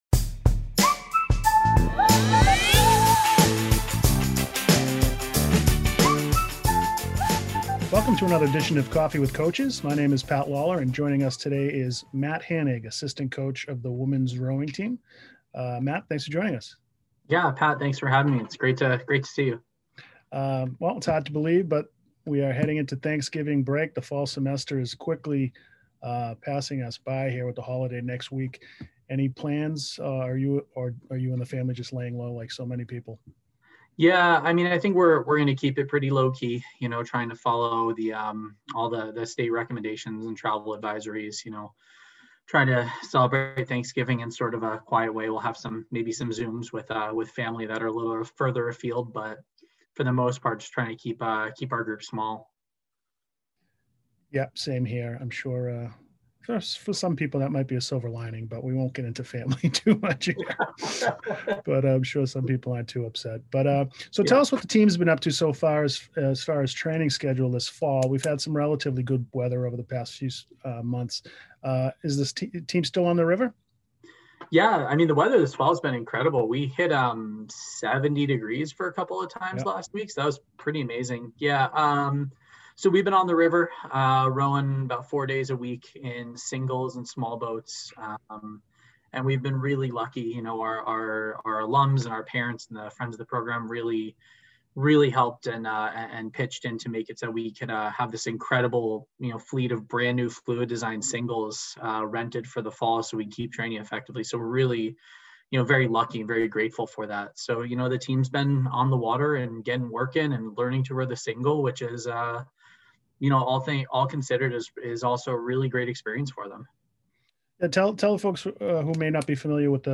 Each week we'll feature an interview with one of our coaches.